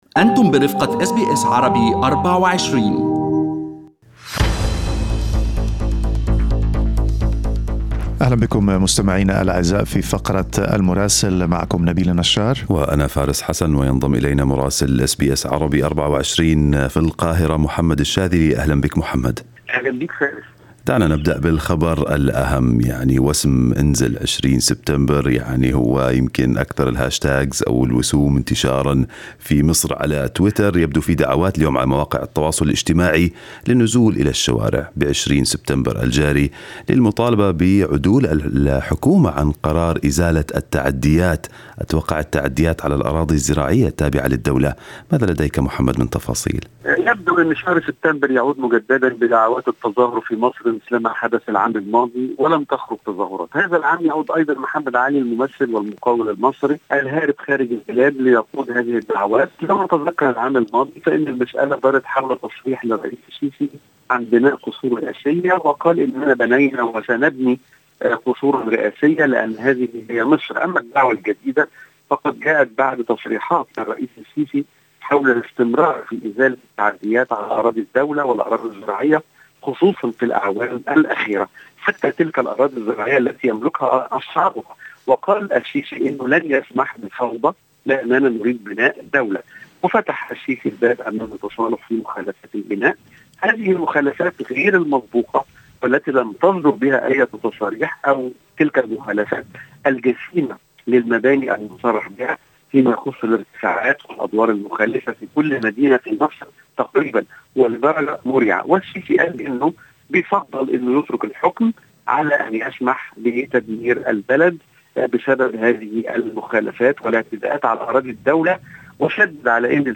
من مراسلينا: أخبار مصر في أسبوع 9/9/2020